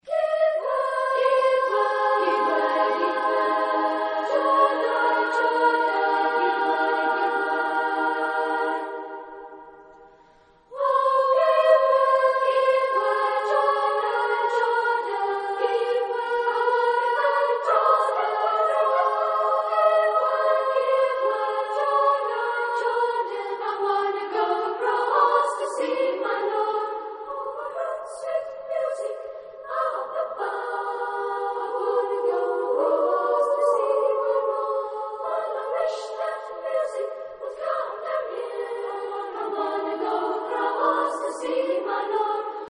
Genre-Style-Form: Spiritual ; Sacred
Type of Choir: SSA  (3 women voices )
Tonality: F minor